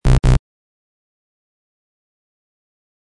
Download wrong sound effect for free.